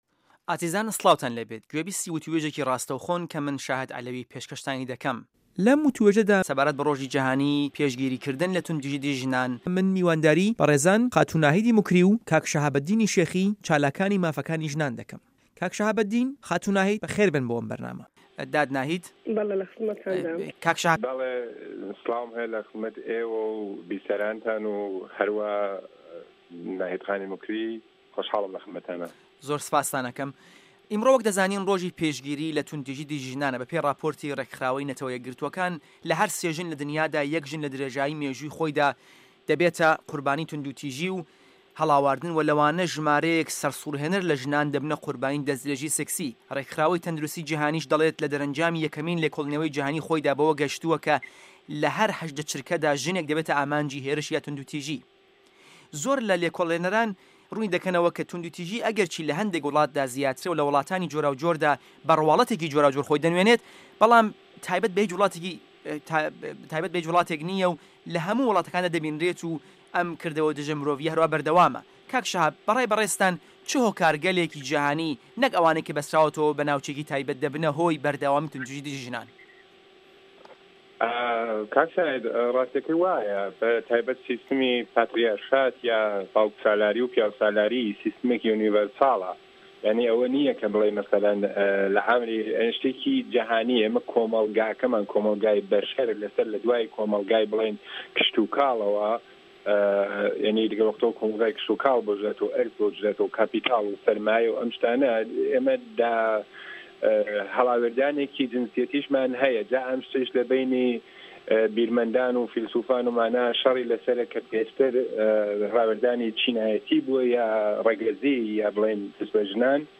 وتووێژی ڕۆژی پێشگیری له توندو تیژی دژی ژنان